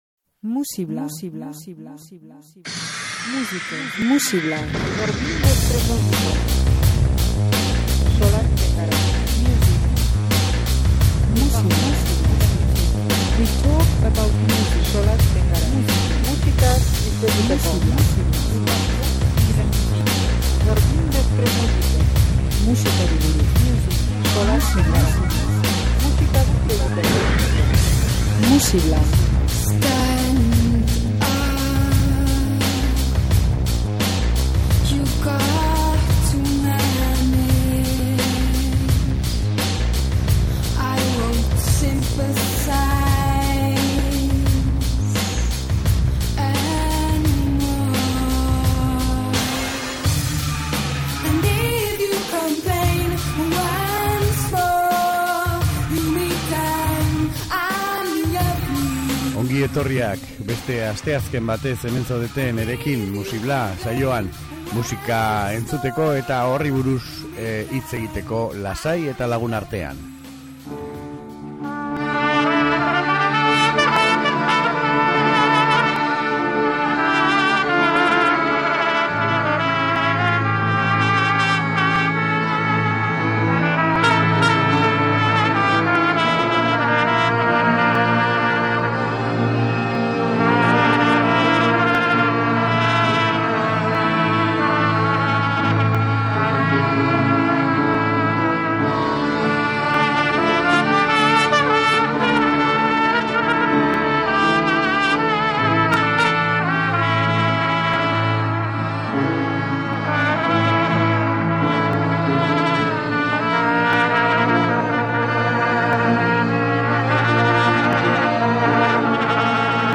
Tangoen errebisioak, psikodelia berria, geruza zenbaezinez sortzen diren melodiak.